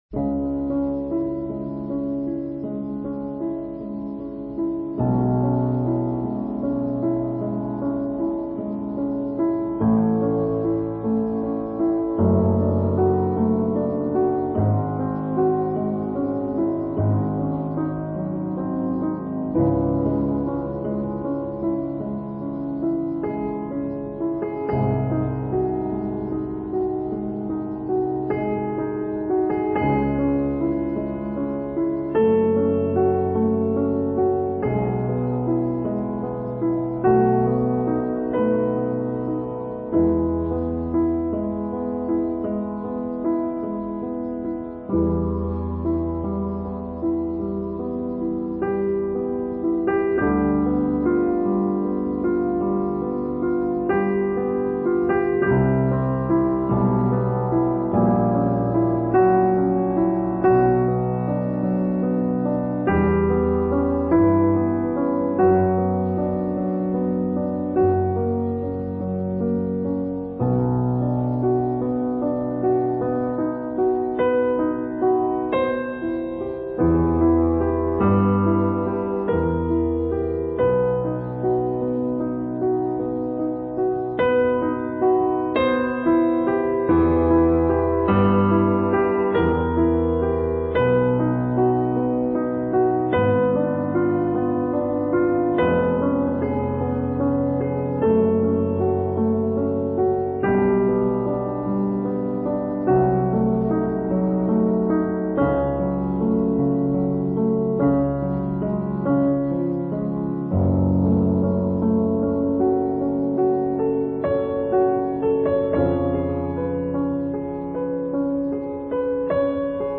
Это не песня, а классическое произведение "Лунная соната" Людвига ван Бетховена, годы жизни 16 декабря 1770 – 26 марта 1827 гг.
04_lyudvig_van_bethoven_sonata.mp3